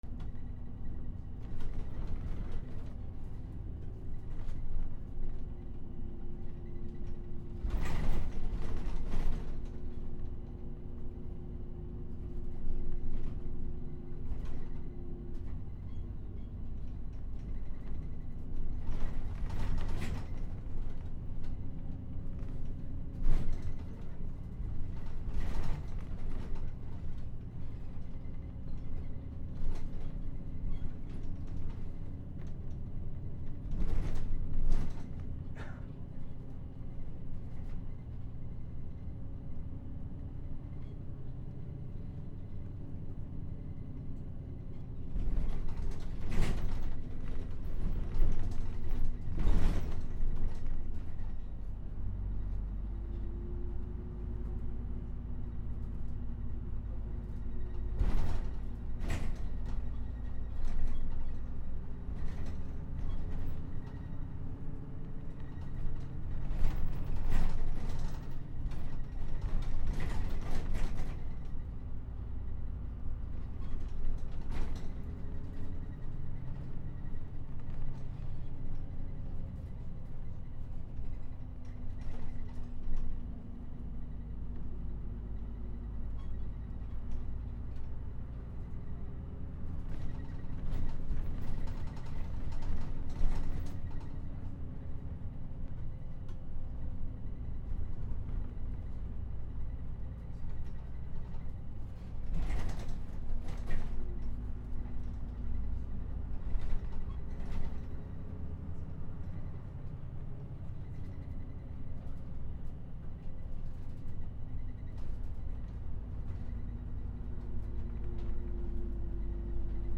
路線バス 車内より
/ E｜乗り物 / E-35 ｜バス
NT4 高野山